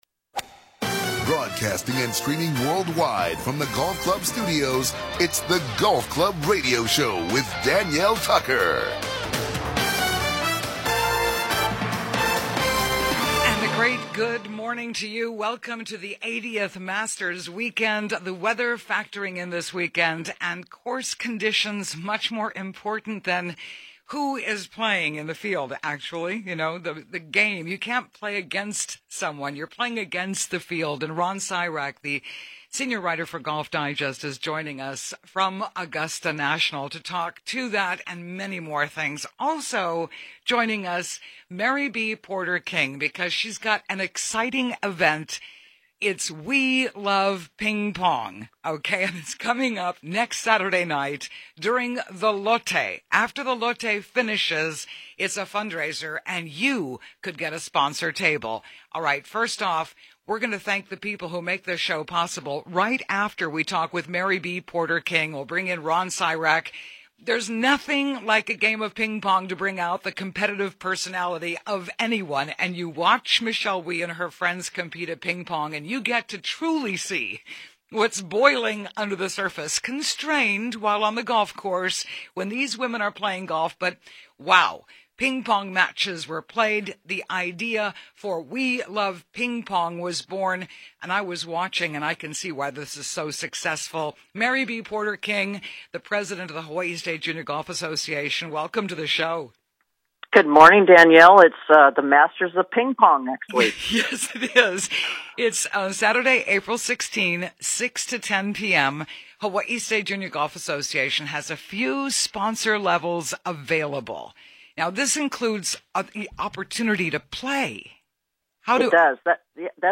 Golf Club Radio Show 4/9/2016